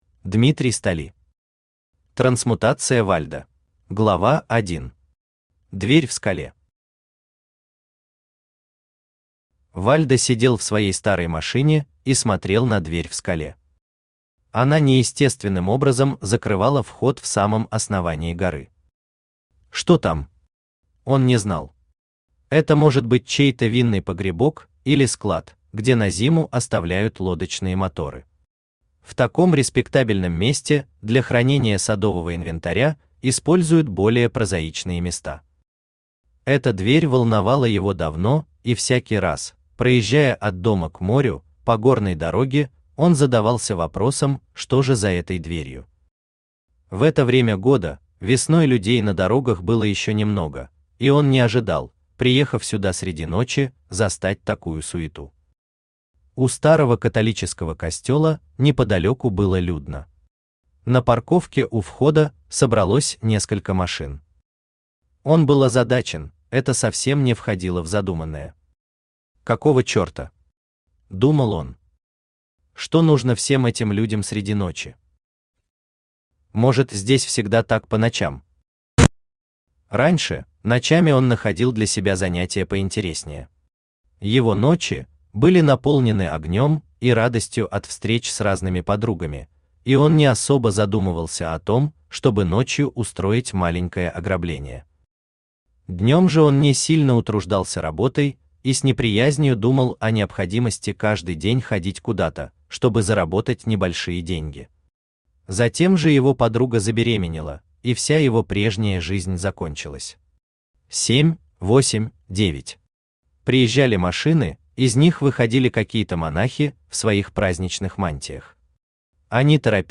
Аудиокнига Трансмутация Вальдо | Библиотека аудиокниг
Aудиокнига Трансмутация Вальдо Автор Дмитрий Столи Читает аудиокнигу Авточтец ЛитРес.